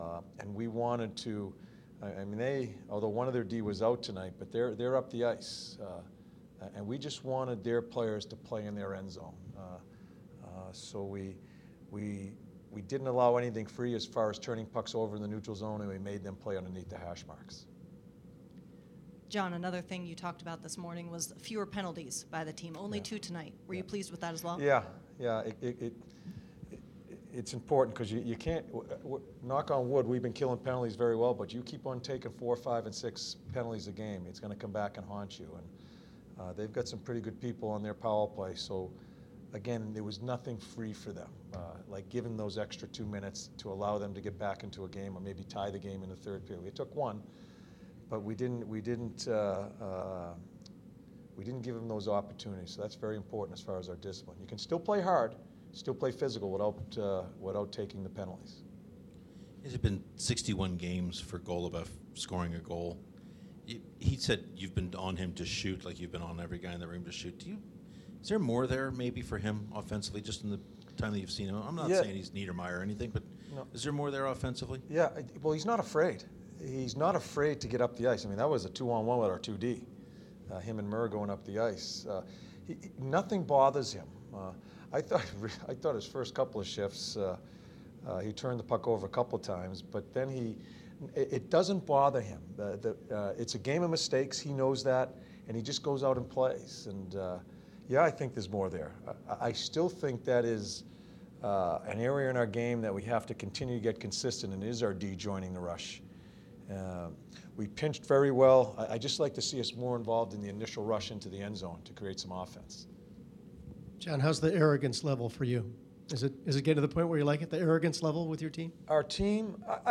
John Tortorella Post-Game in the Jackets 3-1 win over the St. Louis Blues 11-17-15